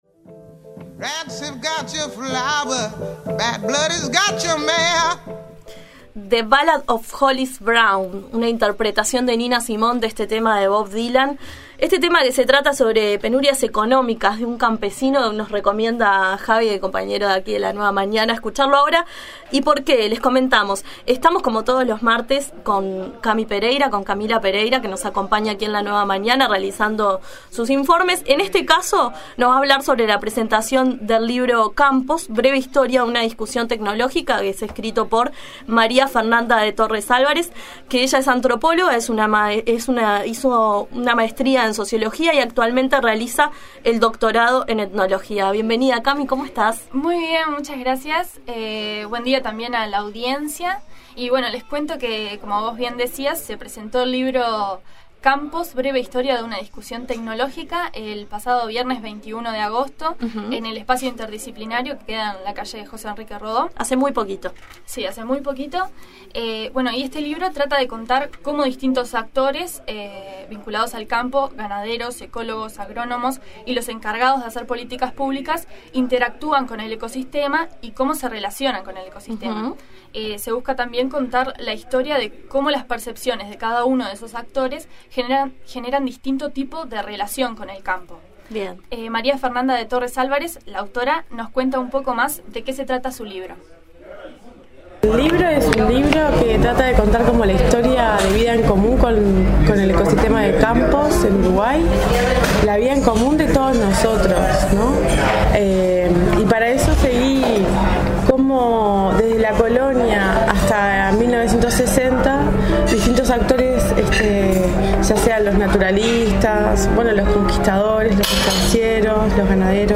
El libro se presentó el viernes 21 de agosto en el Espacio Interdisciplinario de la Udelar.